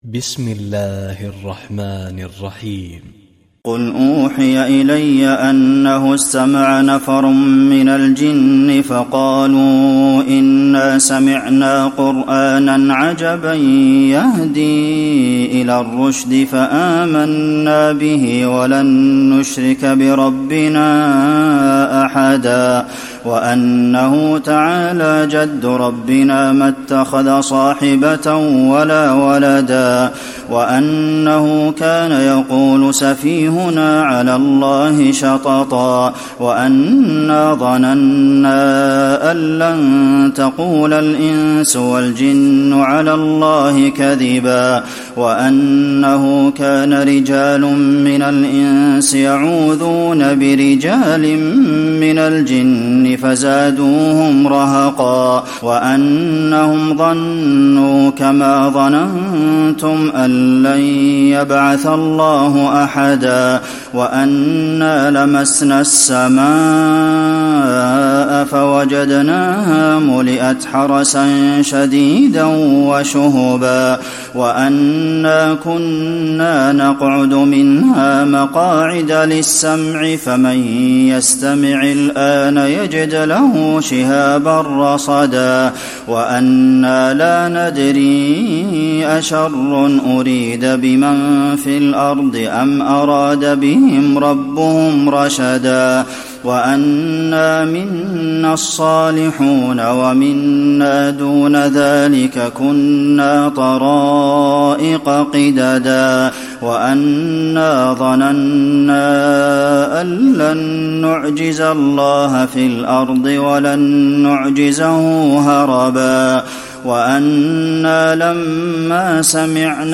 تراويح ليلة 28 رمضان 1435هـ من سورة الجن الى المرسلات Taraweeh 28 st night Ramadan 1435H from Surah Al-Jinn to Al-Mursalaat > تراويح الحرم النبوي عام 1435 🕌 > التراويح - تلاوات الحرمين